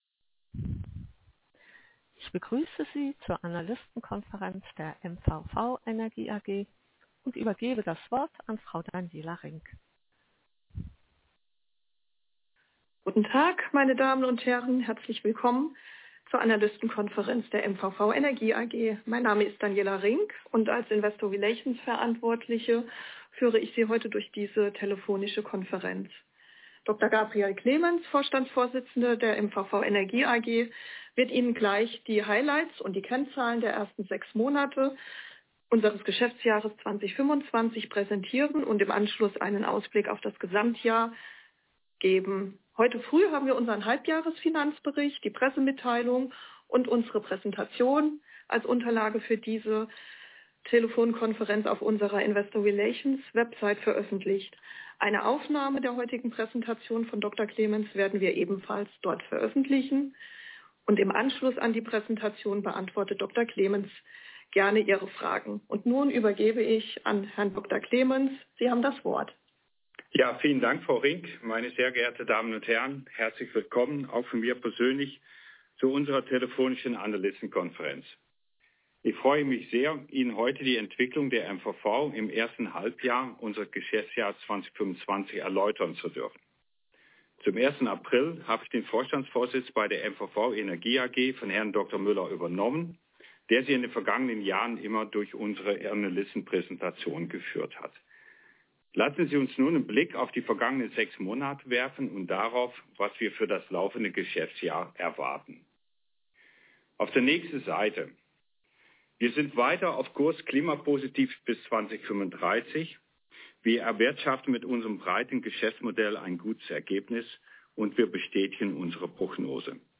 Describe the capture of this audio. Audio Recording of the Analysts' Conference 2025 (only German)